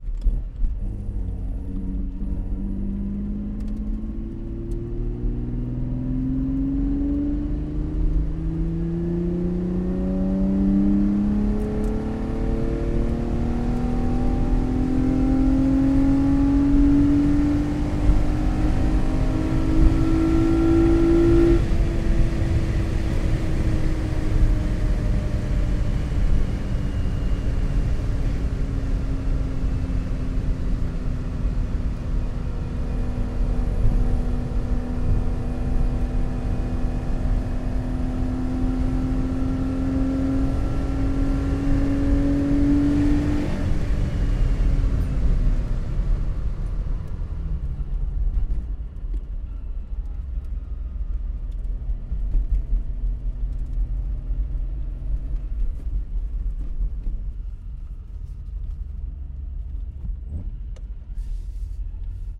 Звук поездки в Ferrari Dino при разгоне